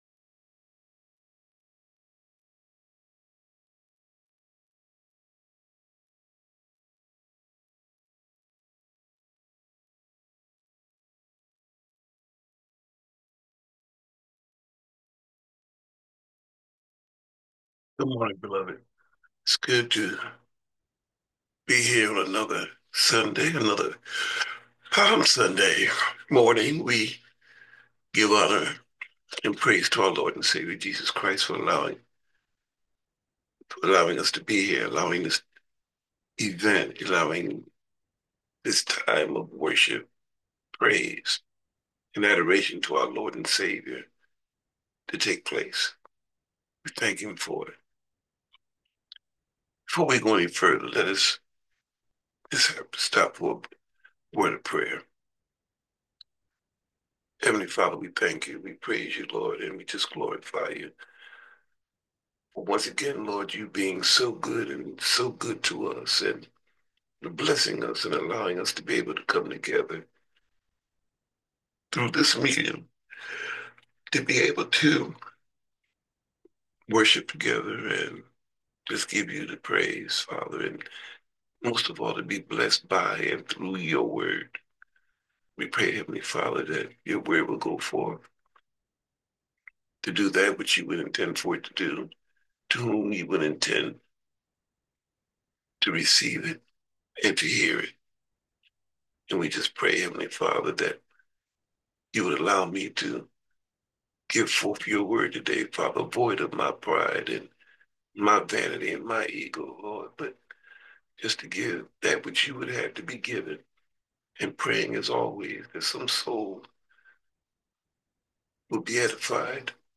Sunday Message HOSANNA
Sunday Sermon, Hosanna